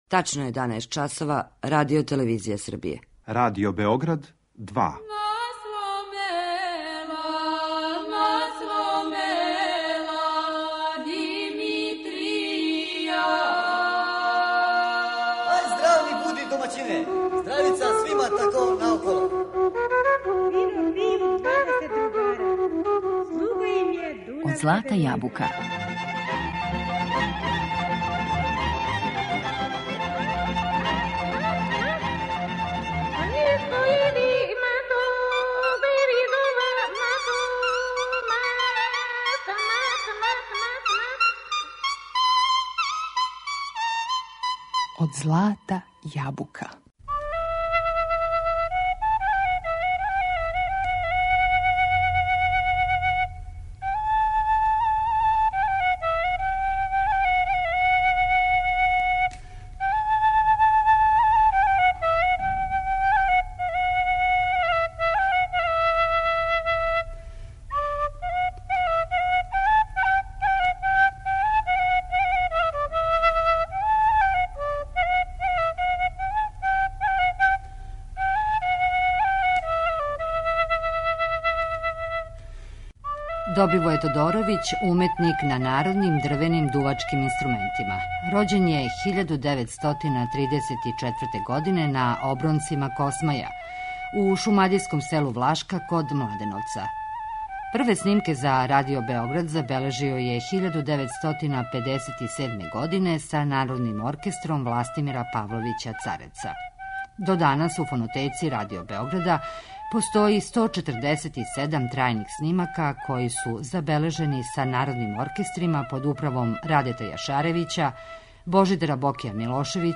уметник на традиционалним дрвеним дувачким инструментима
најпознатији самоуки фрулаш у Србији.